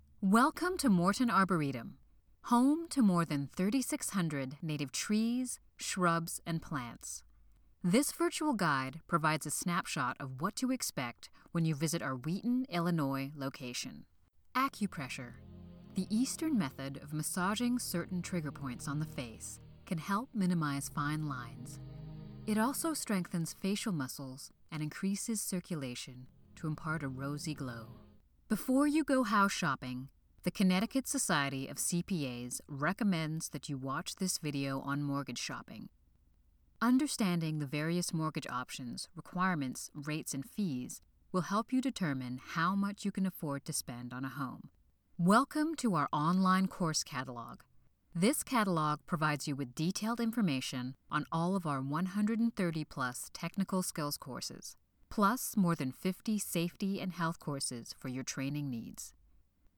Female
English (North American)
Adult (30-50), Older Sound (50+)
E-Learning